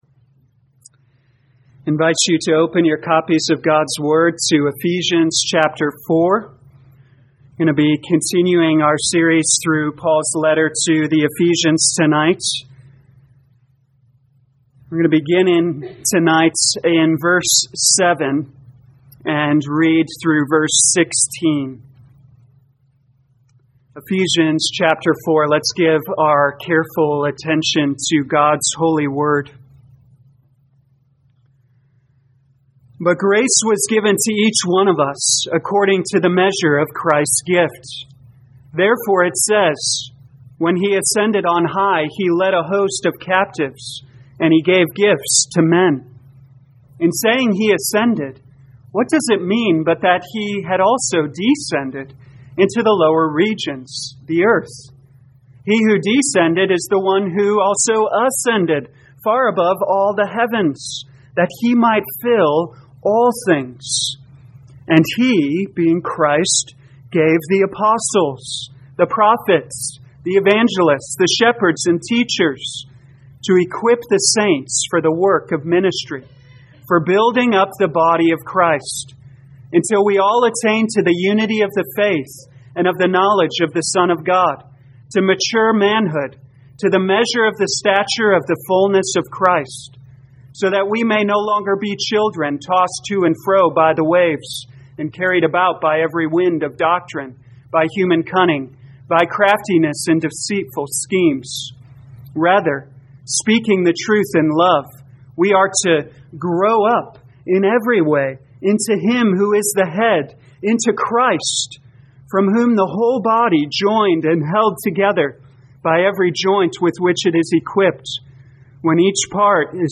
2021 Ephesians Evening Service Download
You are free to download this sermon for personal use or share this page to Social Media. God's Vision for Church Life (Part 2) Scripture: Ephesians 4:7-16